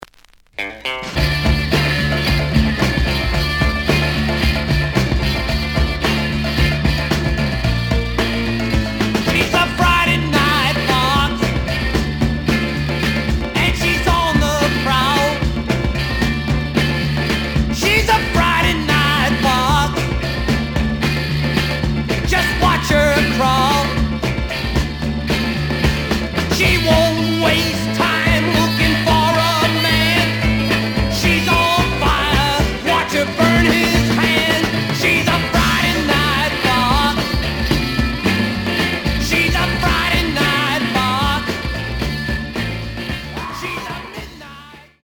The audio sample is recorded from the actual item.
●Genre: Rhythm And Blues / Rock 'n' Roll
●Record Grading: VG~VG+ (傷はあるが、プレイはおおむね良好。Plays good.)